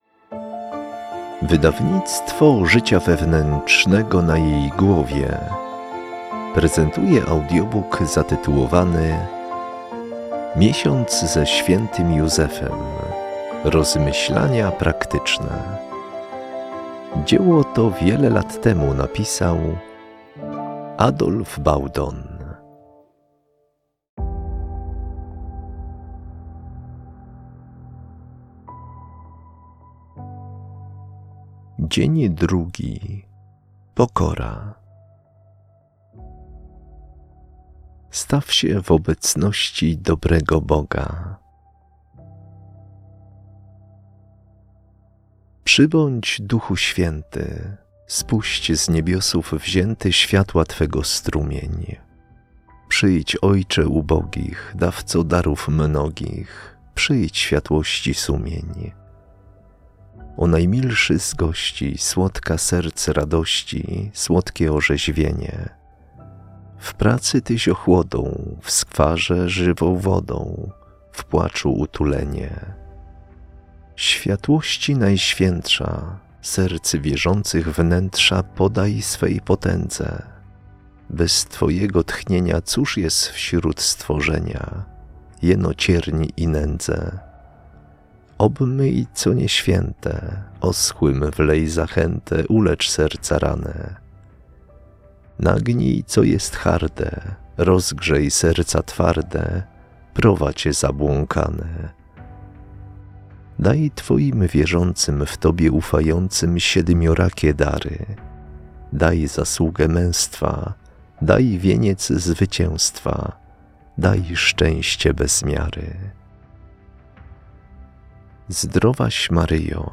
Miesiąc ze św. Józefem – rozmyślania praktyczne - Adolf Baudon - audiobook